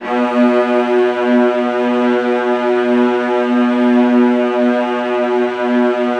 VIOLAS C#3-L.wav